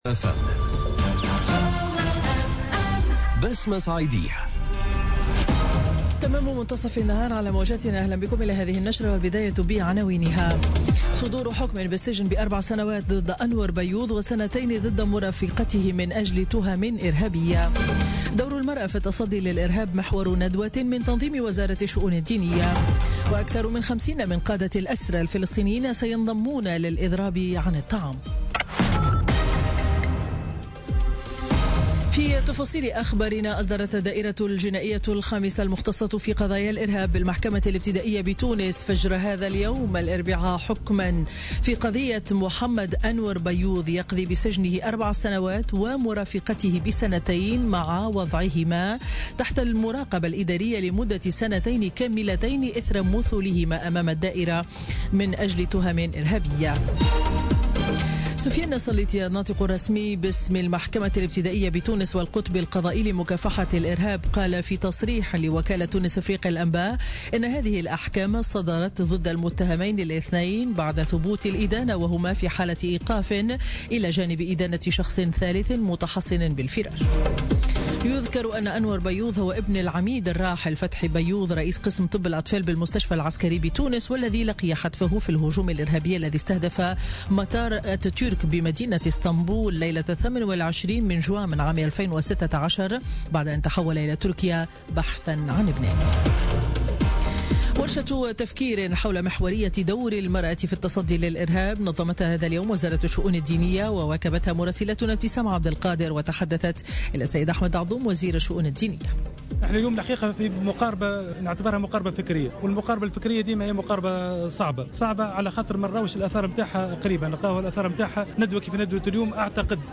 نشرة أخبار منتصف النهار ليوم الإربعاء 3 ماي 2017